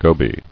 [go·by]